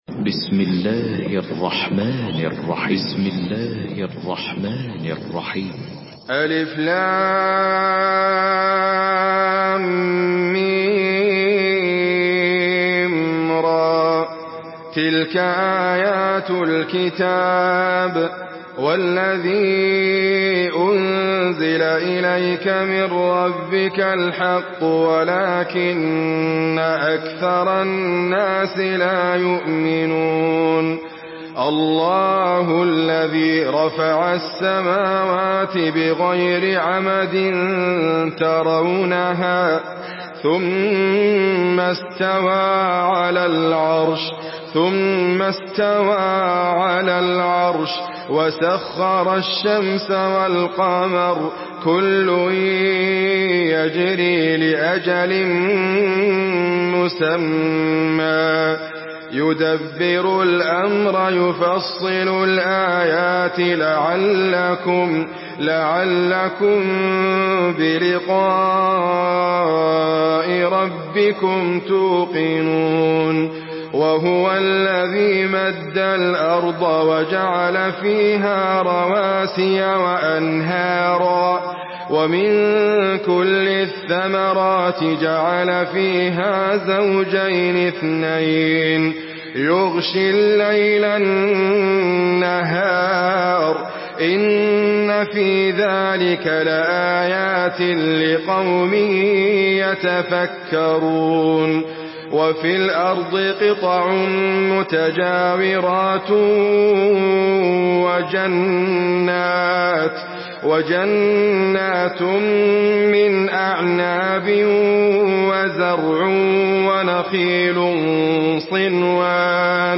Surah Rad MP3 by Idriss Abkar in Hafs An Asim narration.
Murattal Hafs An Asim